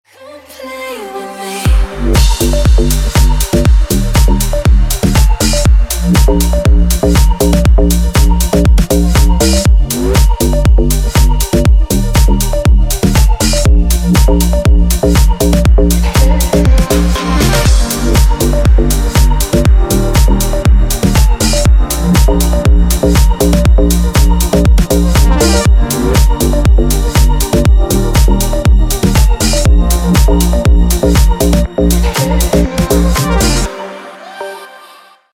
красивые
dance
Electronic
EDM
house